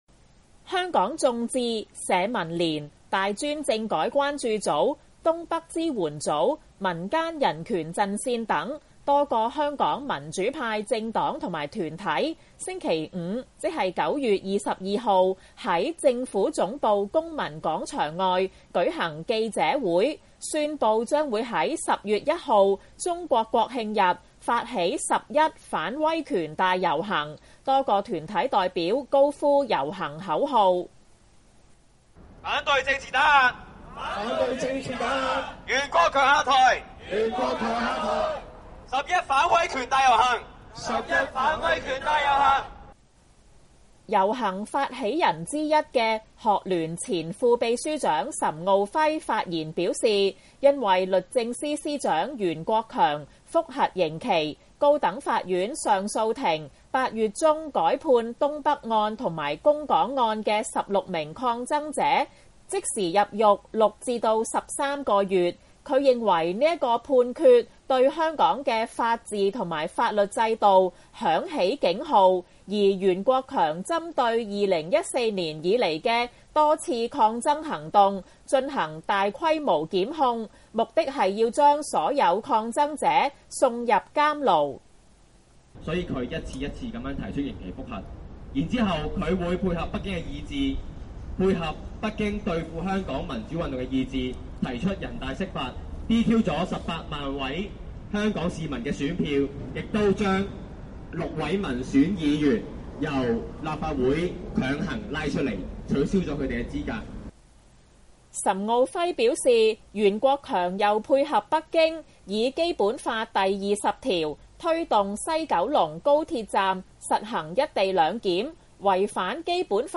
香港眾志、社民連、大專政改關注組、東北支援組、民間人權陣線等，多個香港民主派政黨及團體，星期五(9月22日)在政府總部公民廣場外舉行記者會，宣佈將於10月1日中國國慶日，發起「十一反威權大遊行」，多個團體代表高呼遊行口號。
團體代表高呼口號：反對政治打壓、袁國強下台、「十一反威權大遊行」。